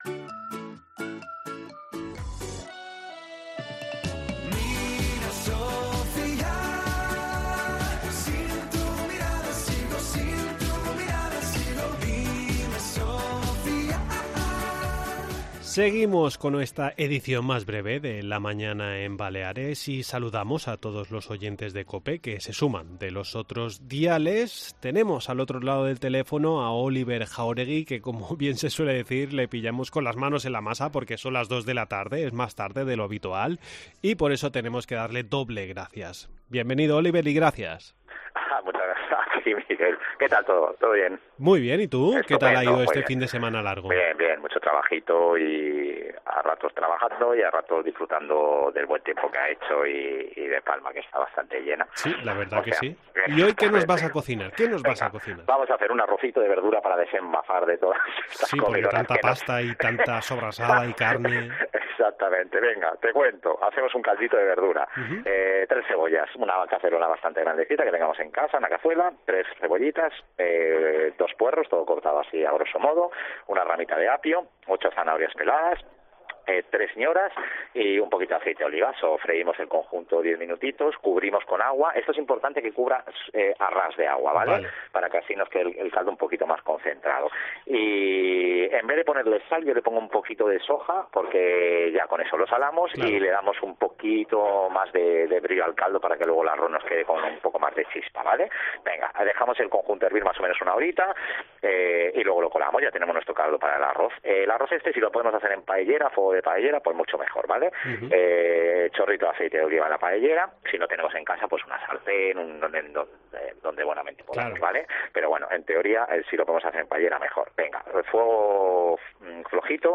Entrevista en La Mañana en COPE Más Mallorca, lunes 10 de abri de 2023